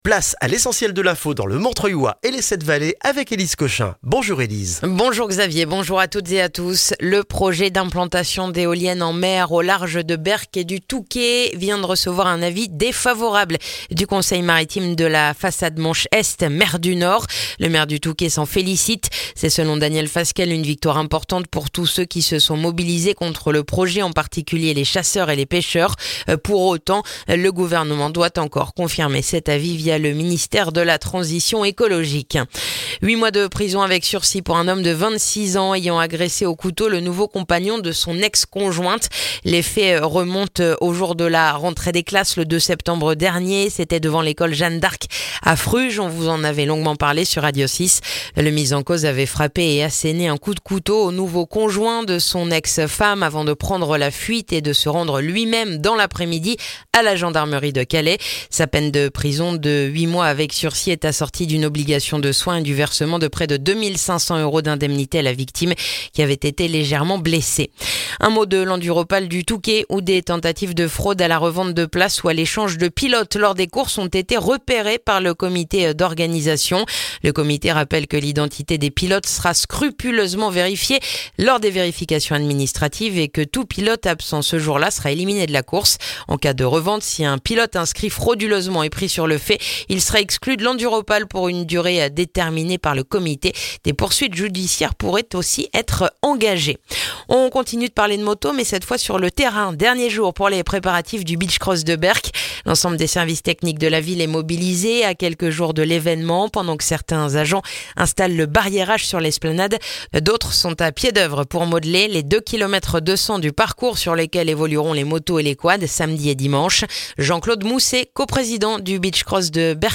Le journal du jeudi 10 octobre dans le montreuillois